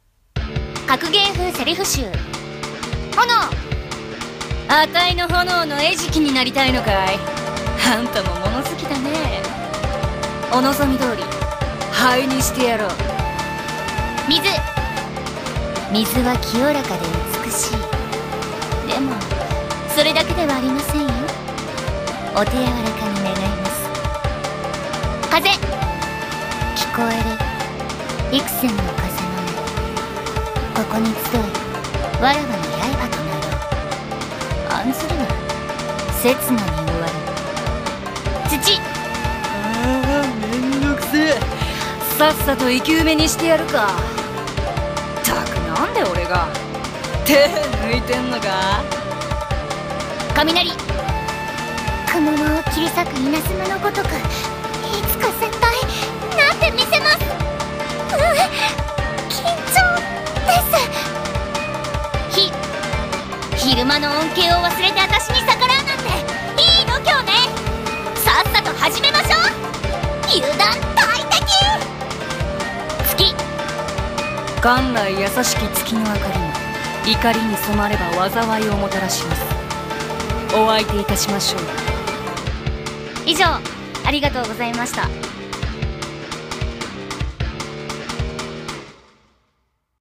【演じ分け】格ゲー風台詞集【妖怪】